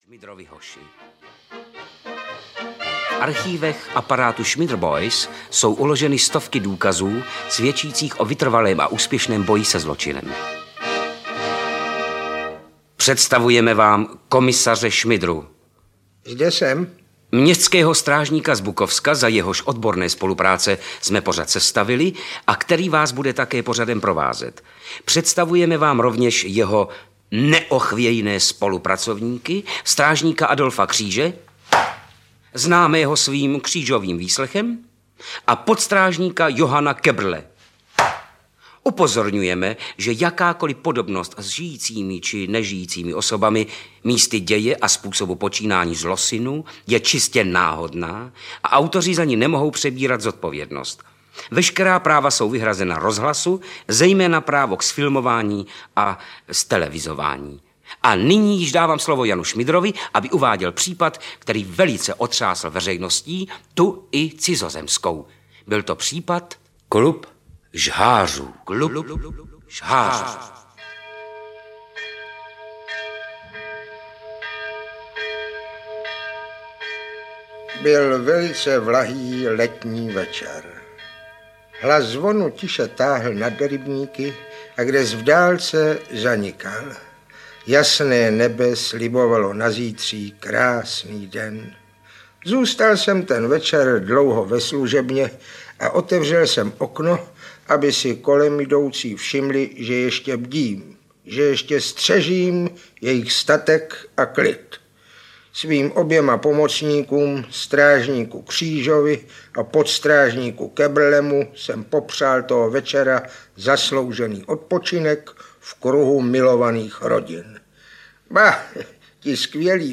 Ukázka z knihy
Pokračování úspěšné kolekce dramatizací s popleteným detektivem Janem Šmidrou.Tentokrát v povídkách Klub žhářů a Zločin cválá na bílém koni. Raritní nahrávky Československého rozhlasu z roku 1967 jsou plné tehdejších hereckých hvězd - Hlinomaz, Řanda, Kopřiva, Teplý, Mareš, Mach, Salač aj. Nad nimi bdí moudrý inspektor, tentokrát v podání Rudolfa Deyla mladšího.